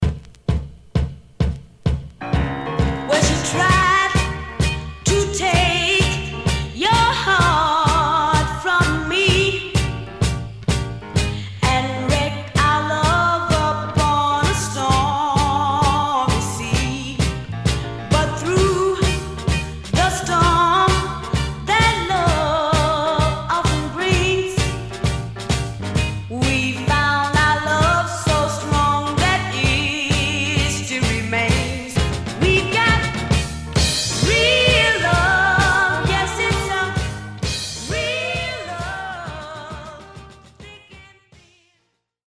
Stereo/mono Mono